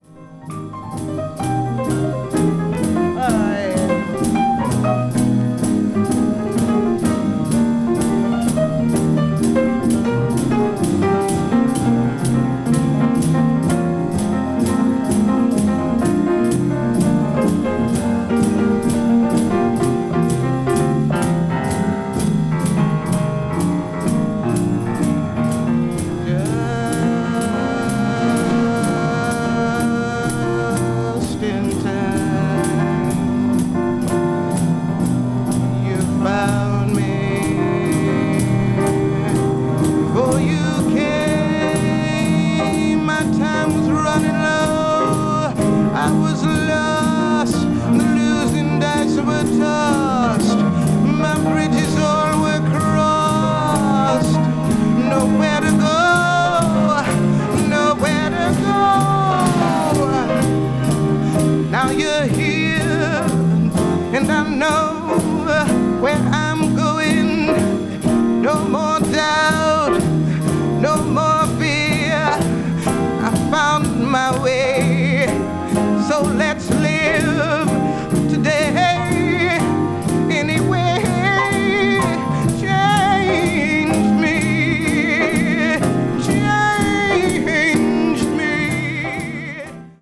1968年に出演したMontreux Jazz Festivalでのライブ・レコーディング音源を収録。